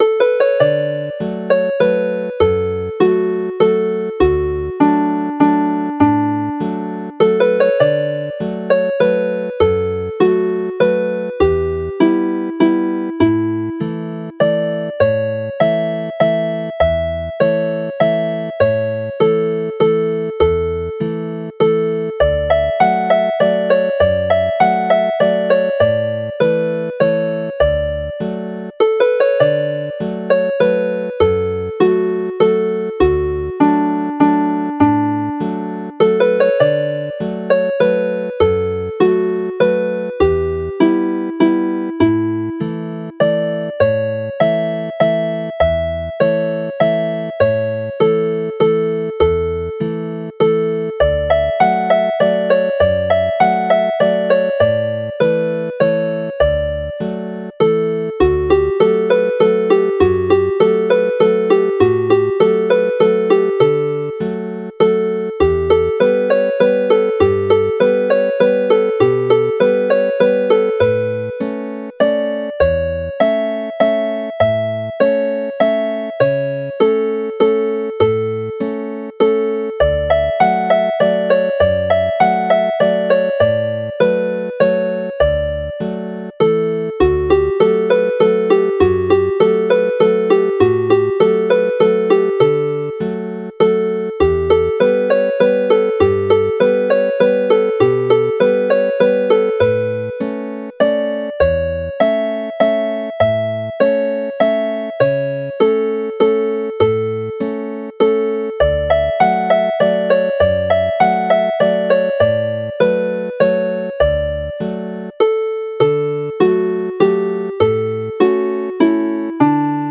Chwarae'r set yn araf
Play the set slowly